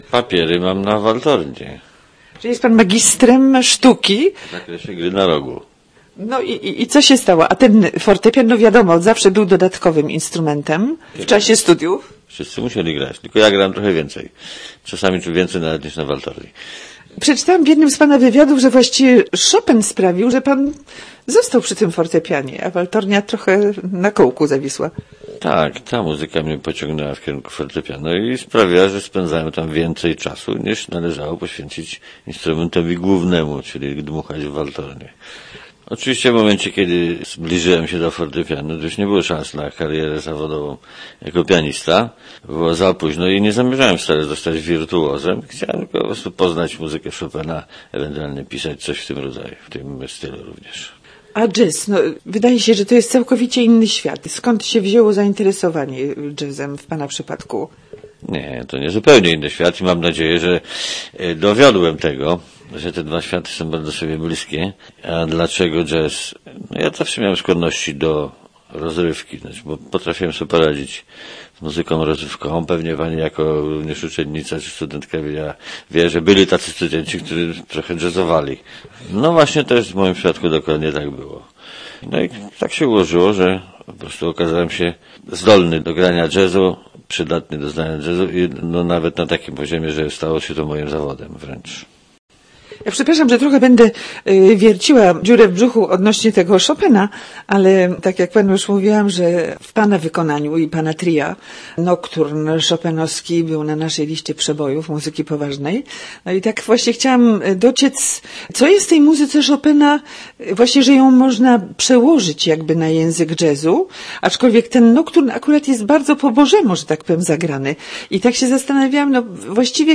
W Auli Nova odbył się koncert zorganizowany przez Zakład Jazzu i Muzyki Rozrywkowej poznańskiej Akademii Muzycznej. Gościem specjalnym koncertu był wybitny polski pianista jazzowy Andrzej Jagodziński.